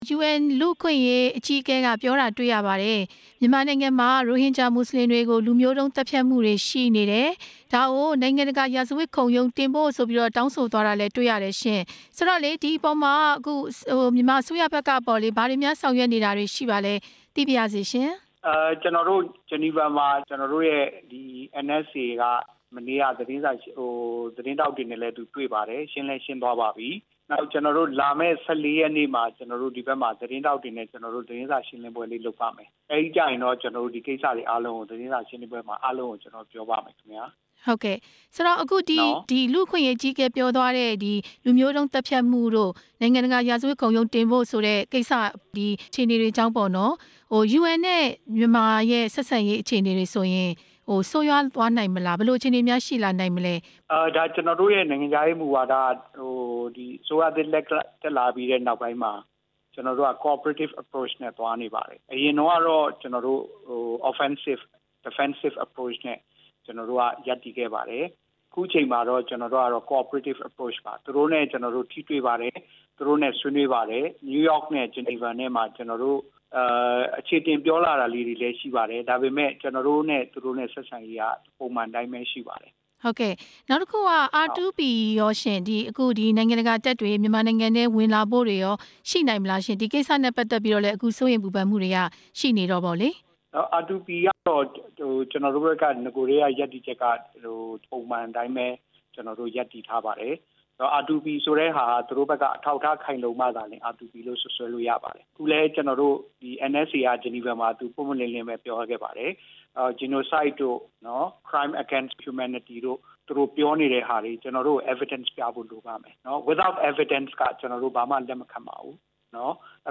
နိုင်ငံတကာရာဇဝတ်မှုခုံရုံးတင်ရေး ကုလကြိုးပမ်းချက်အပေါ် နိုင်ငံခြားရေးဝန်ကြီးဌာနနဲ့ မေးမြန်းချက်